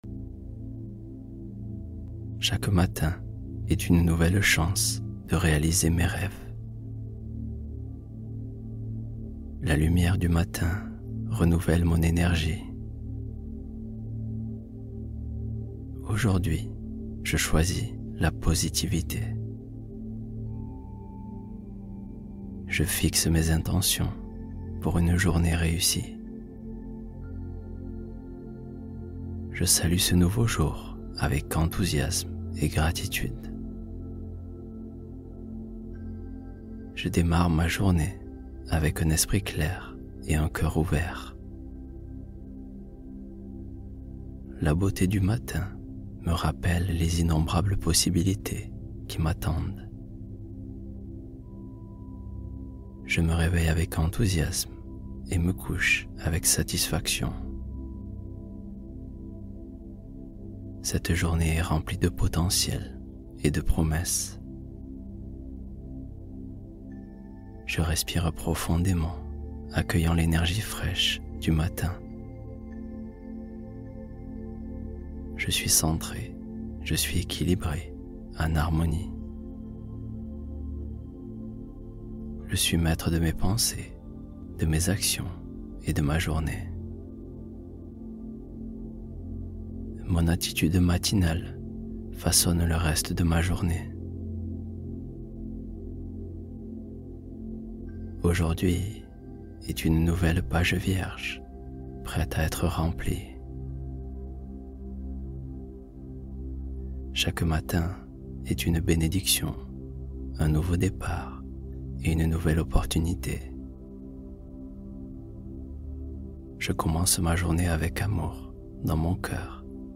S’accepter pleinement : méditation profonde d’accueil de soi